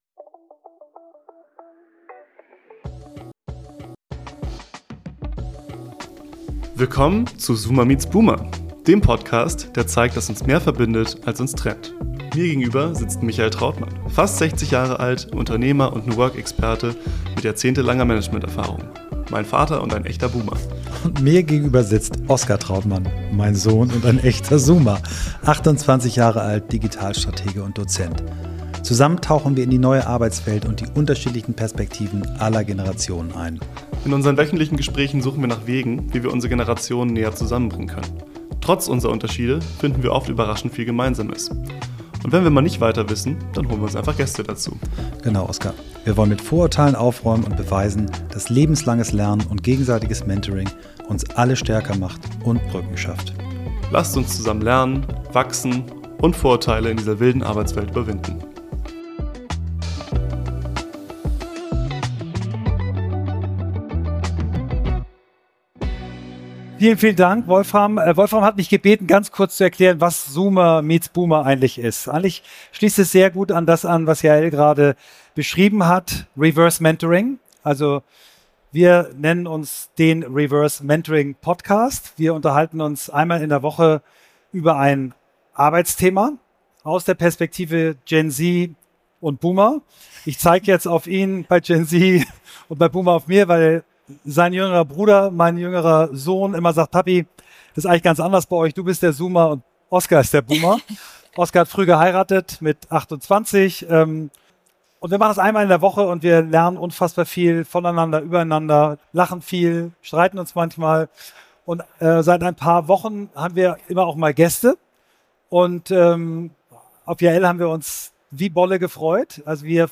Die aktuelle Folge von Zoomer Meets Boomer haben wir live auf der Orgatec in Köln aufgenommen.